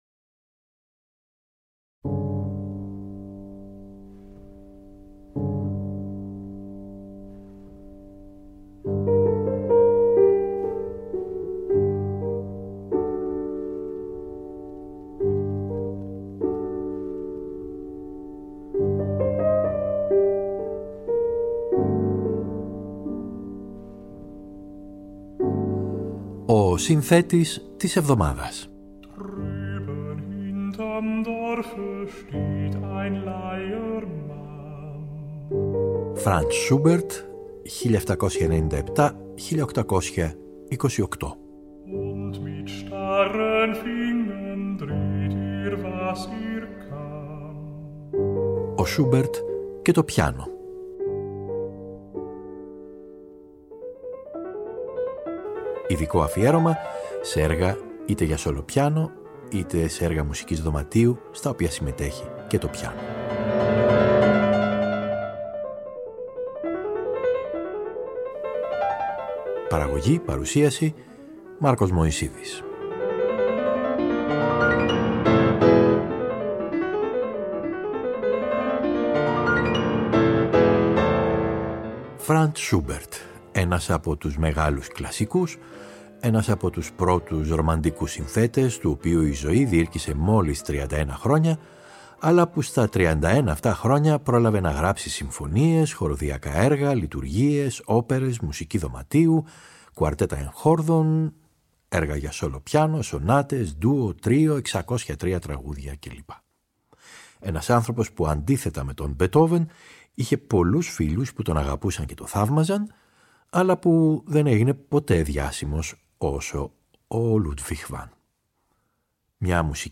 Εβδομάδα αφιερωμένη στον Franz Schubert (1797 – 1828) Γύρω από το πιάνο.
Το πενθήμερο αυτό είναι αφιερωμένο στα έργα του Schubert , είτε για solo piano , είτε με τη συμμετοχή του οργάνου αυτού – δηλαδή έργα για πιάνο duo, piano trio, lieder κ.λ.π. Καλή σας ακρόαση !